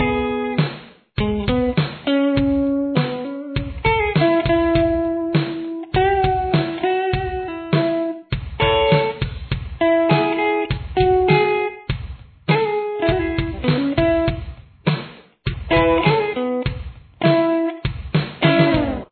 Verse
Guitar 3